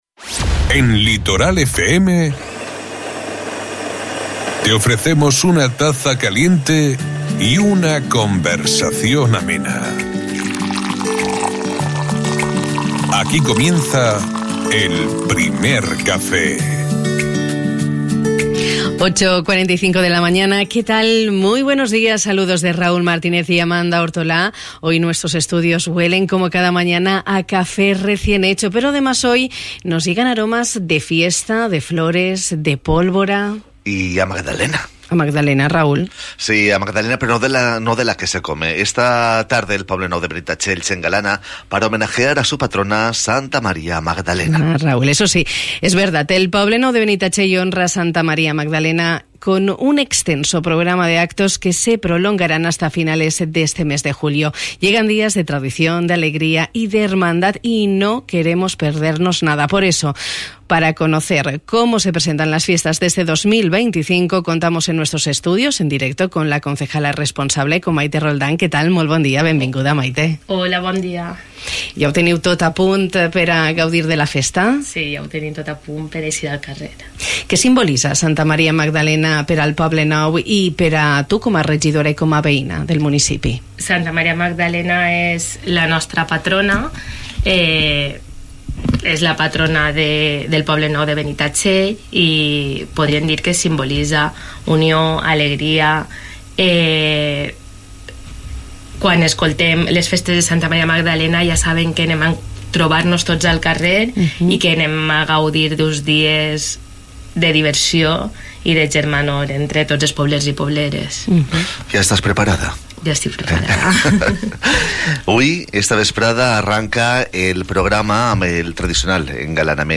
Por eso, con el fin de conocer cómo se presentan los festejos de este 2025, hemos compartido nuestro Primer Café con la concejala de Fiestas, Mayte Roldán. Con ella hemos repasado los actos más tradicionales y todas las novedades que se han incorporado a la programación de este año.